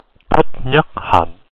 Thích Nhất Hạnh (/ˈtɪk ˈnɑːt ˈhɑːn/ TIK NAHT HAHN; Vietnamese: [tʰǐk̟ ɲə̌t hâjŋ̟ˀ]
, Huế dialect: [tʰɨt̚˦˧˥ ɲək̚˦˧˥ hɛɲ˨˩ʔ]; born Nguyễn Xuân Bảo; 11 October 1926 – 22 January 2022) was a Vietnamese Thiền Buddhist monk, peace activist, prolific author, poet and teacher,[2] who founded the Plum Village Tradition, historically recognized as the main inspiration for engaged Buddhism.[3] Known as the "father of mindfulness",[4] Nhất Hạnh was a major influence on Western practices of Buddhism.[2]